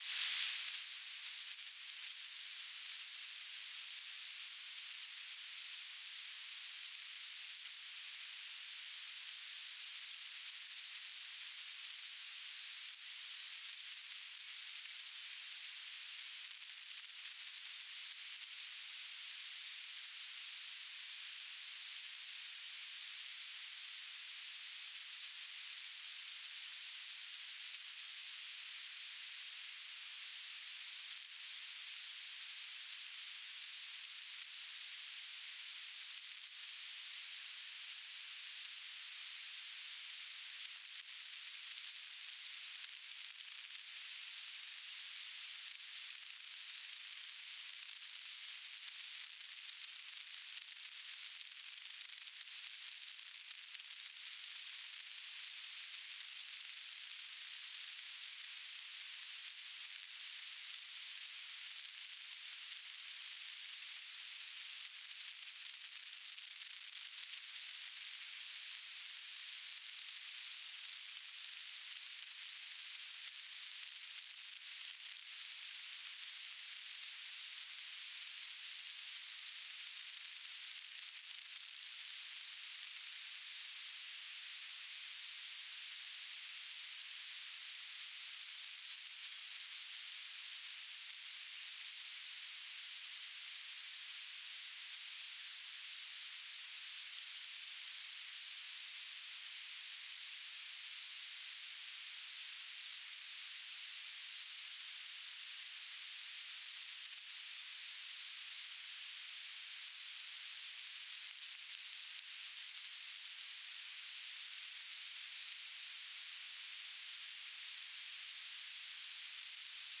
base on this obs over indonesia 2025-12-18 00:38 UTC